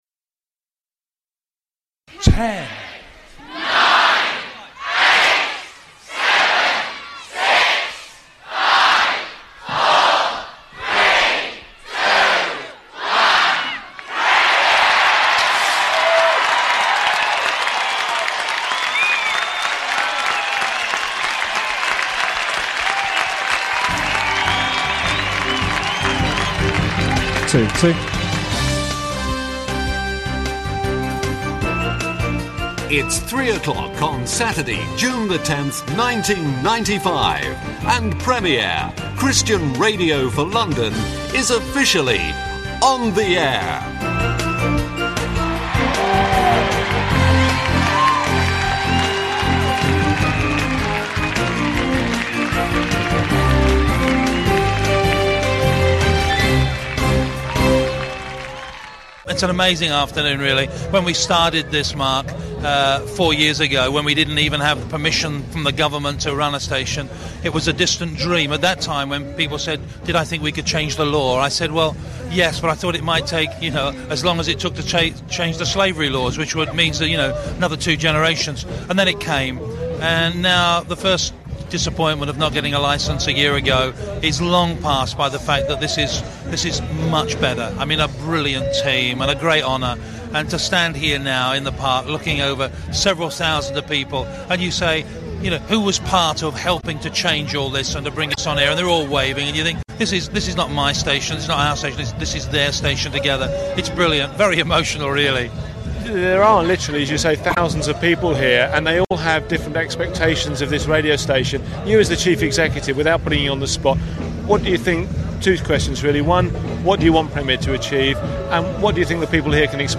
Facebook Twitter Headliner Embed Embed Code See more options It seemed to be due to an inadvertent oversight that a Christian radio station could not hold a radio licence, so there was considerable joy for the pioneers when, at last, a station like Premier Radio could launch. Hear here the countdown at Battersea Park in 1995